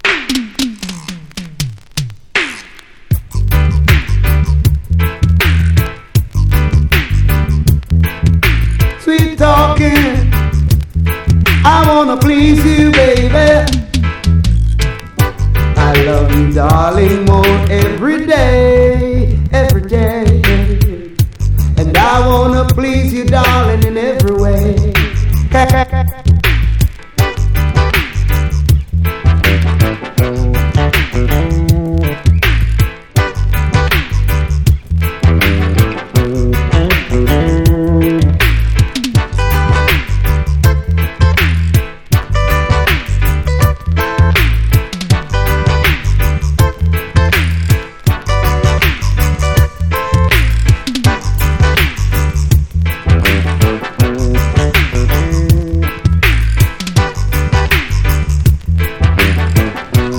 SKA/ROCKSTEADY
最後まで軽快に飛ばすオーセンティック・スカ殿堂サウンド！